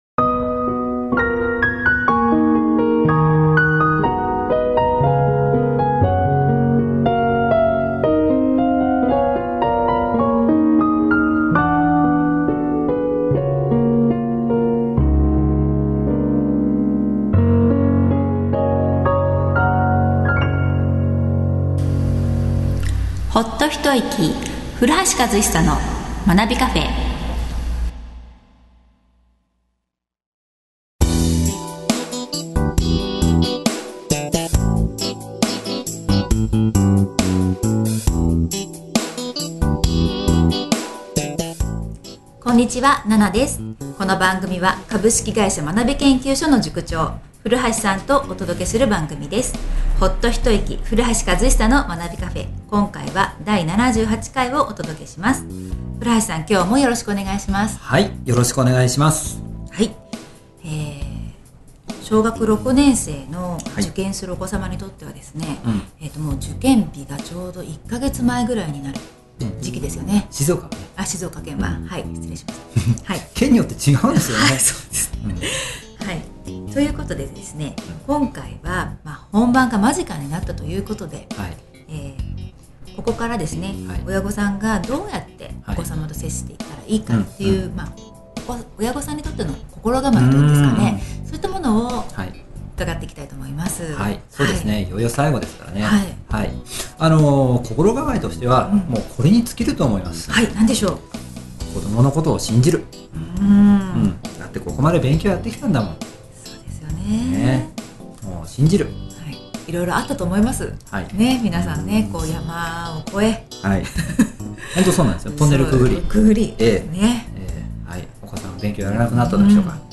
教育について日常会話が繰り広げられる「まなびcafe」。